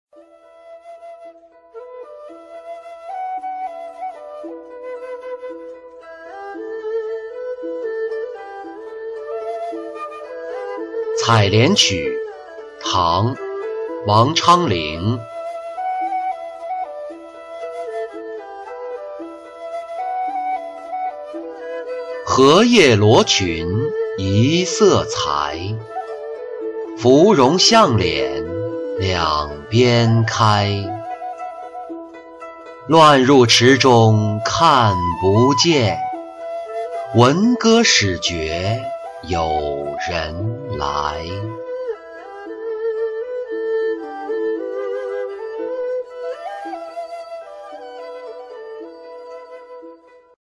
采莲曲二首·其二-音频朗读